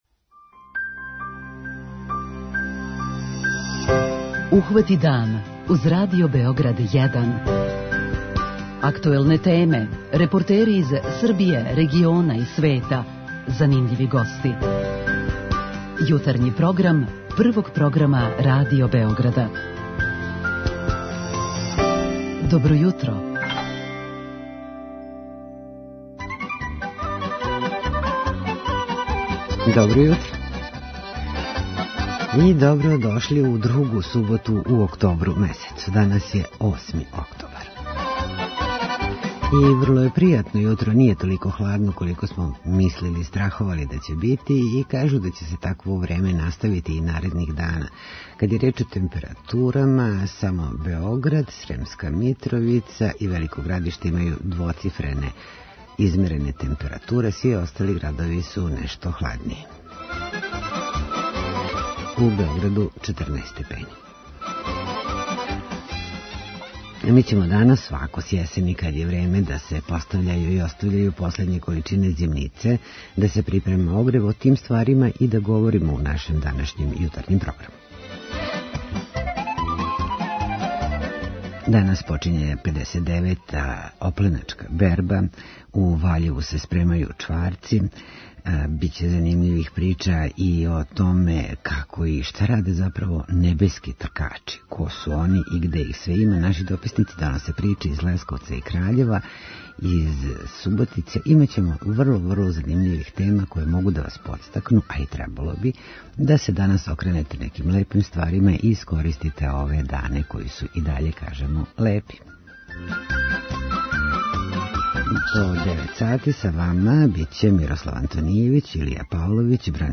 Наши дописници доносе приче из Лесковца и Краљева, а сервисним информацијама ћемо испратити и актуелна збивања у већим градовима.